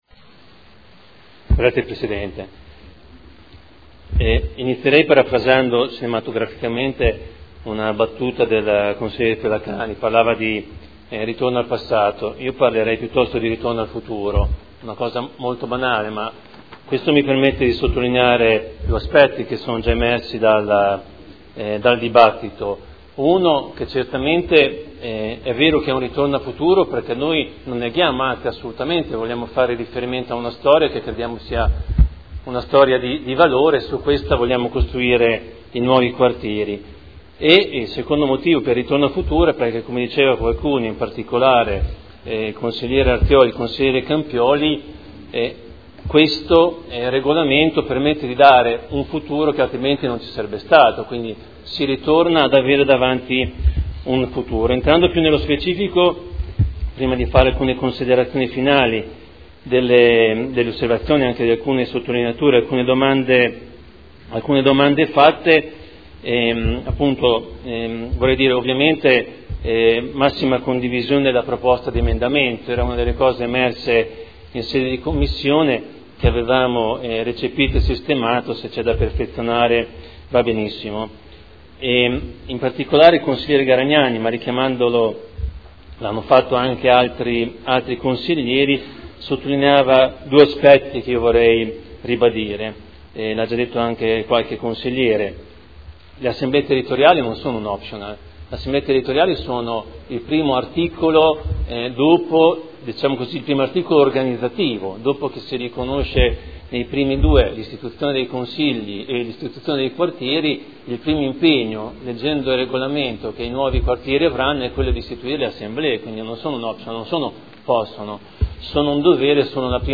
Seduta del 3 aprile. Proposta di deliberazione: Regolamento di prima attuazione della partecipazione territoriale – Approvazione. Dibattito